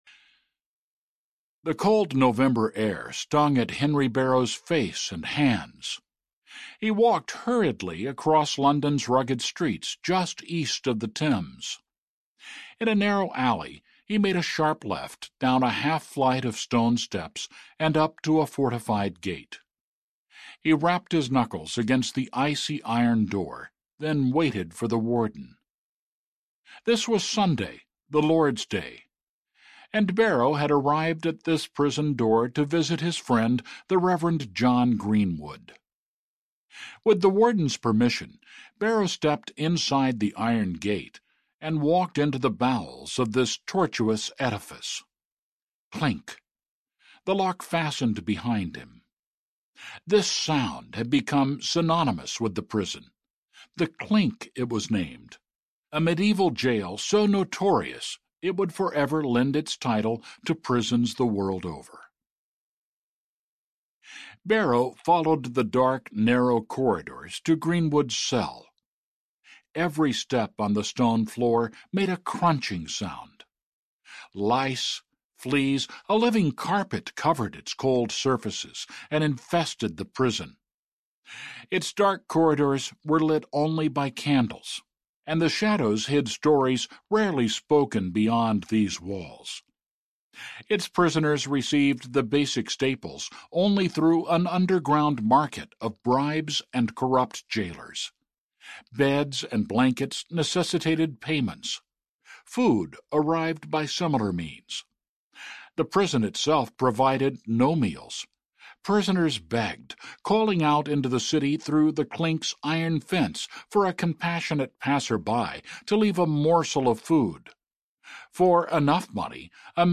They Came for Freedom Audiobook
Narrator
8.2 Hrs. – Unabridged